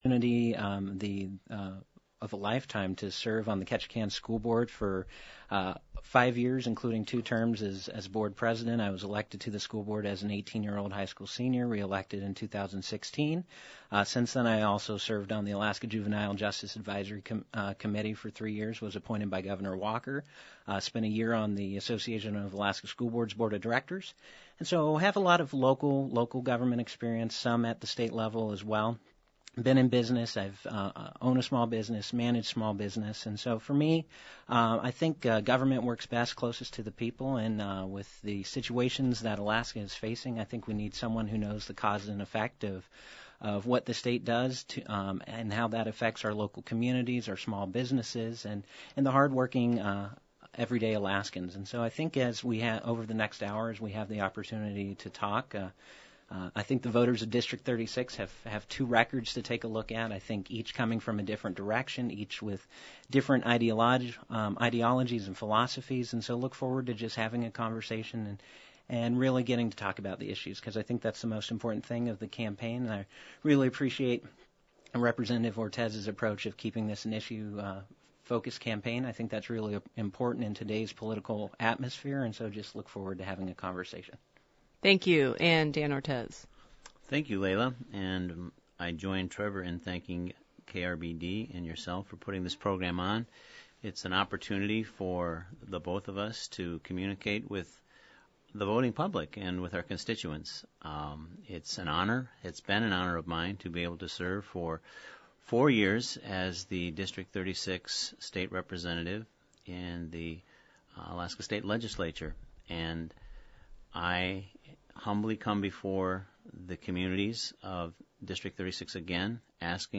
Two candidates are vying for the House District 36 seat, representing Ketchikan, Metlakatla , Hydaburg, Hyder and Wrangell. Incumbent Dan Ortiz, who is unaffiliated, and Republican challenger Trevor Shaw sat down with KRBD for an on-air forum Tuesday evening.